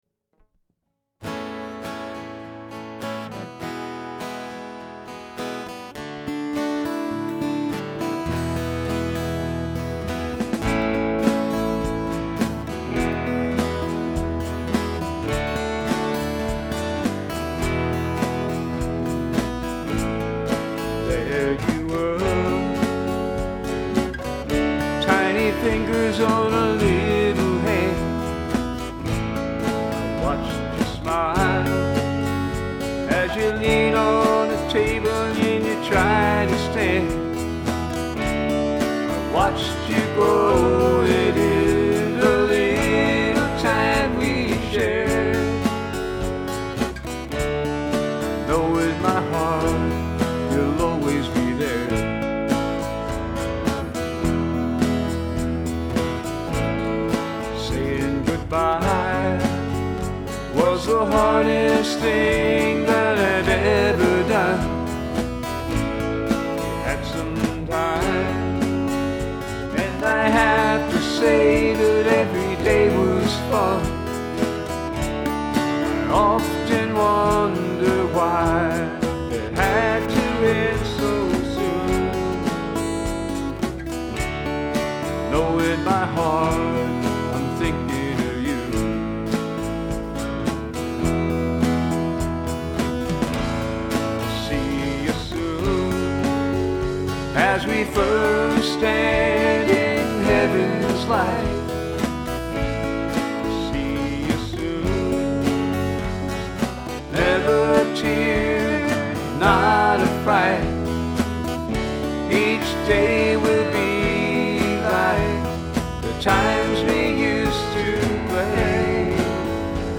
This project is a two CD set recorded in my own study with a few dear friends for the sake of friendship, good times, and lots of fond memories.
acoustic guitar, ukulele, bass guitar, lead vocals, backup vocals, lead guitar, keyboard, percussion
acoustic guitar, electric guitar, backup vocals
drums